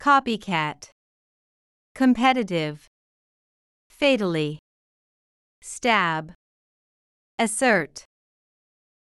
copycat /ˈkɑː.pi.kæt/（名）模倣者、真似をする人
competitive /kəmˈpɛt̬.ə.t̬ɪv/（形）競争の激しい、競争的な
fatally /ˈfeɪ.t̬əl.i/（副）致命的に、命取りになるほどに
stab /stæb/（動）刺す、突き刺す
assert /əˈsɝːt/（動）主張する、断言する